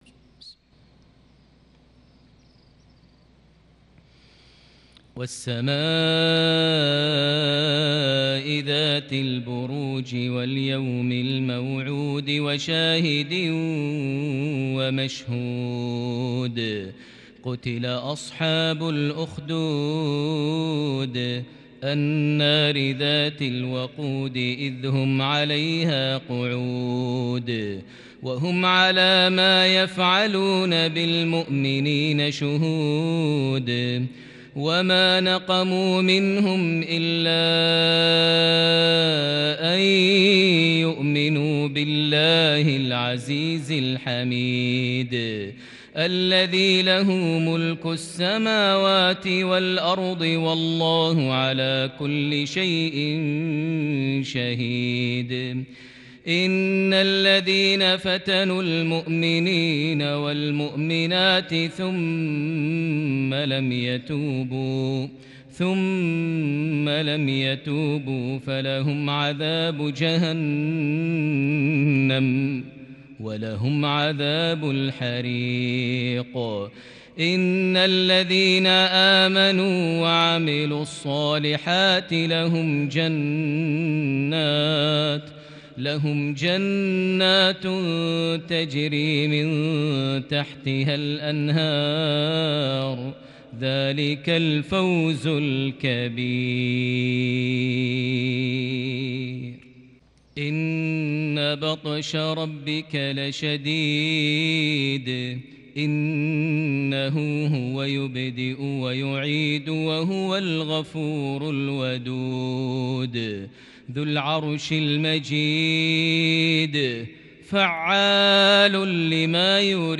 (فعالُ لما يريد) تلاوة شجية تأسرك لسورة البروج |مغرب 27 صفر 1442هـ > 1442 هـ > الفروض - تلاوات ماهر المعيقلي